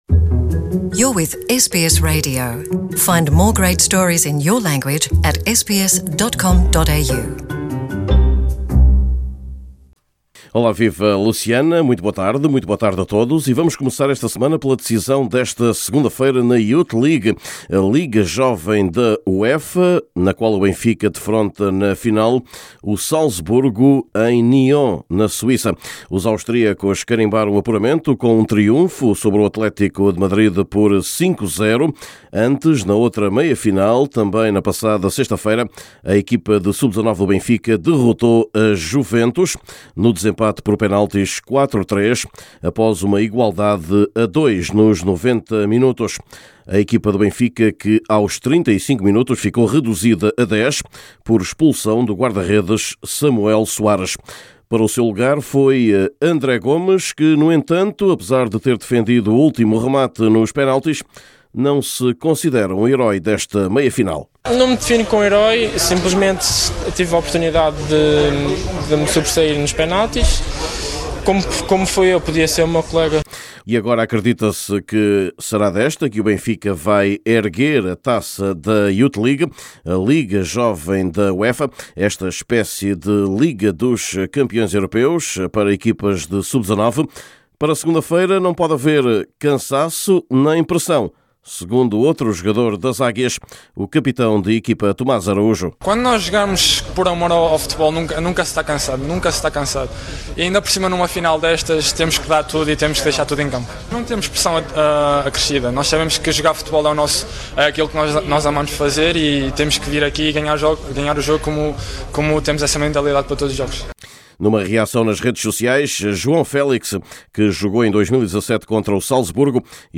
Também neste boletim esportivo, lugar para as contas do título nacional ou para o ténis, quando está a arrancar a edição deste ano do Estoril Open em ténis, o torneio luso do ATP.